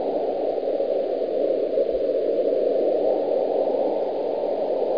00047_Sound_Pyr.SFX-Wind.mp3